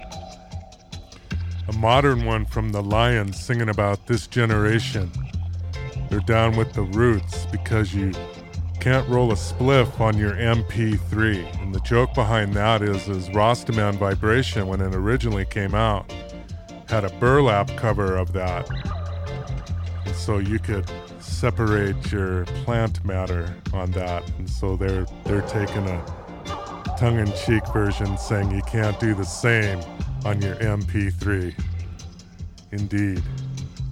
So Cal roots group